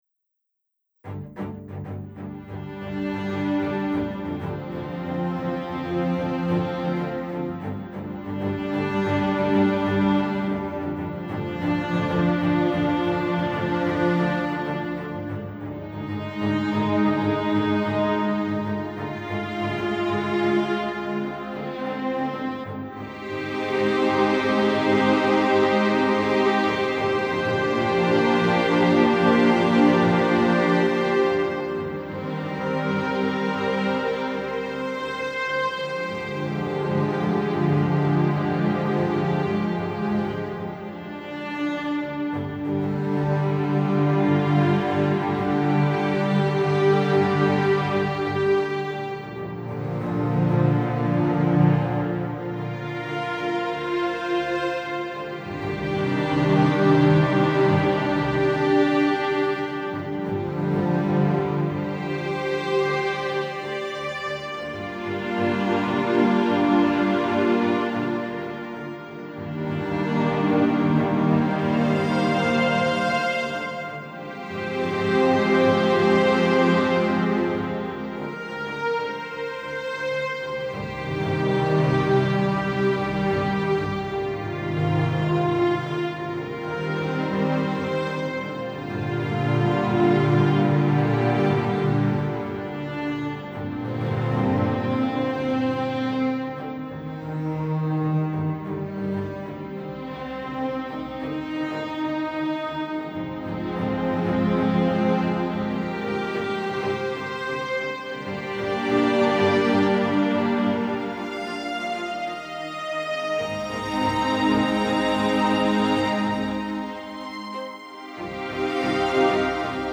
ロング暗い穏やか